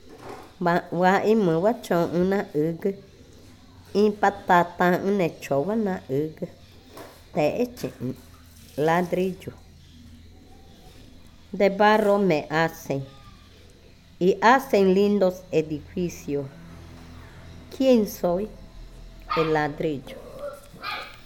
Adivinanza 23. El ladrillo
Cushillococha